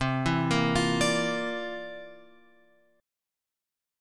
C11 chord {8 7 8 10 x 10} chord